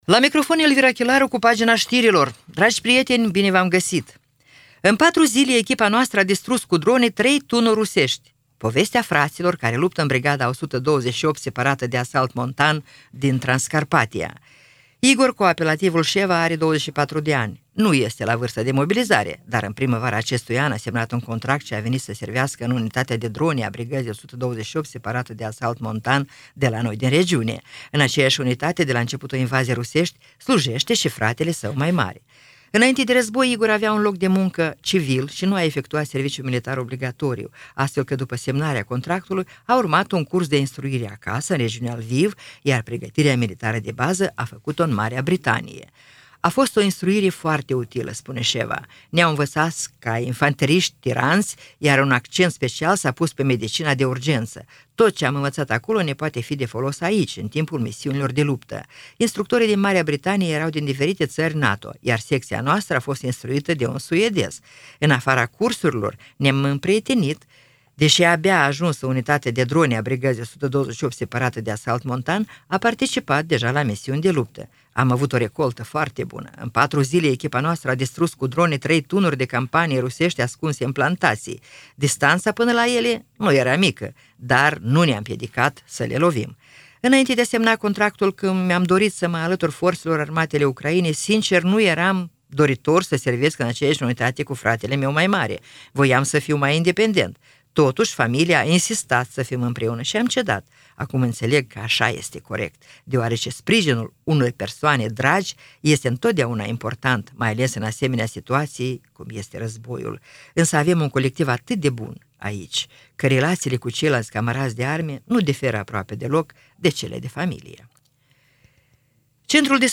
Știri Radio Ujgorod – 13.11.2024, ediția de seară
Știri de la Radio Ujgorod.